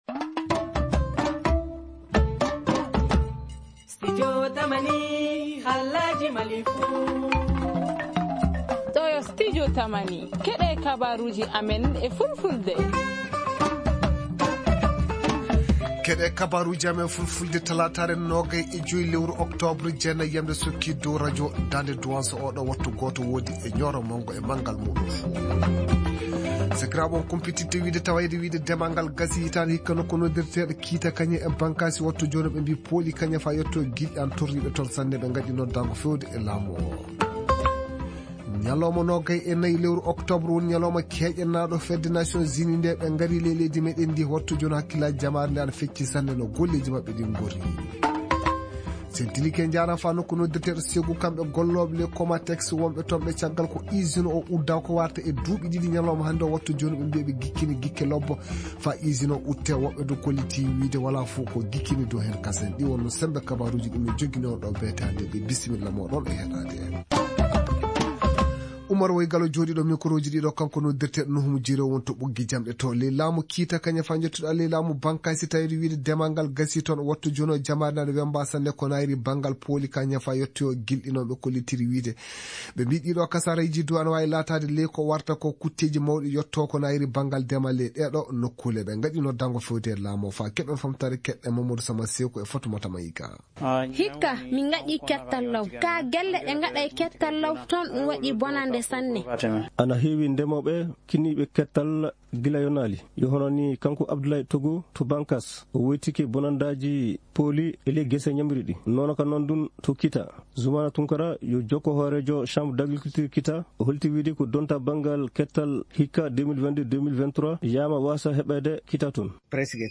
Ci-dessous, écoutez le développement de ces titres dans nos journaux en français et en langues nationales :